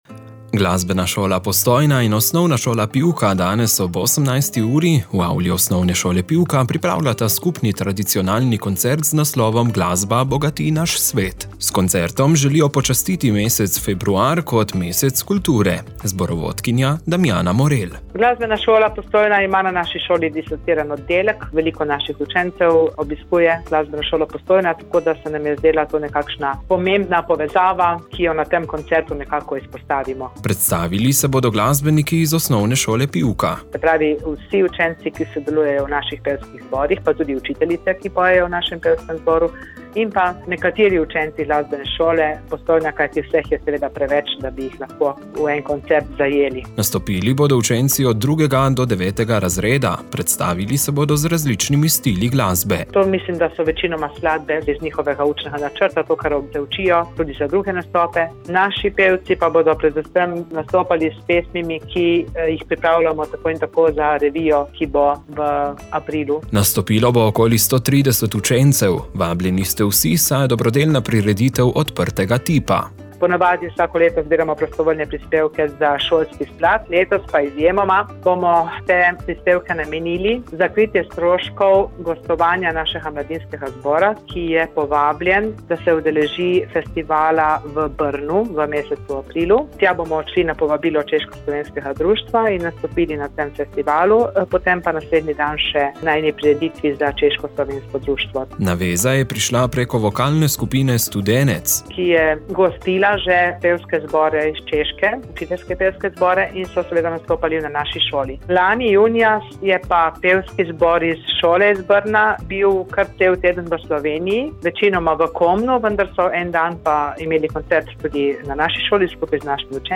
kr19-skupni-nastop-glasbene-sole-postojna-in-os-pivka.mp3